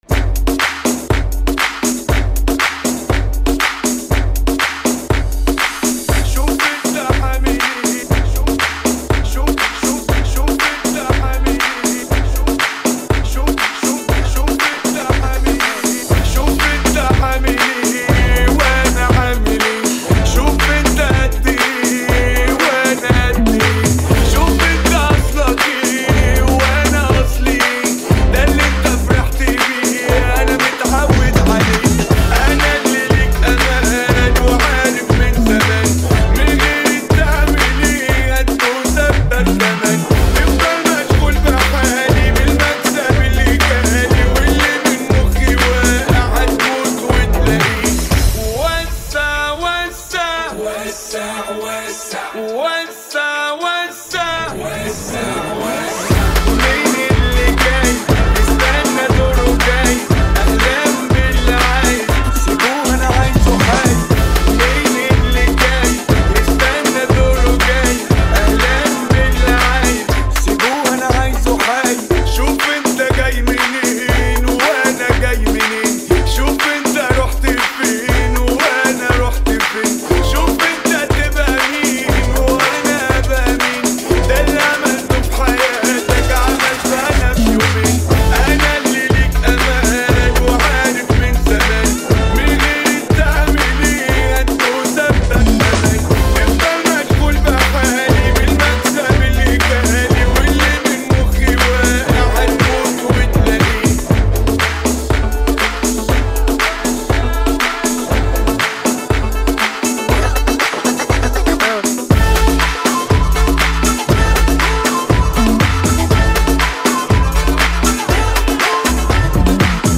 120 bpm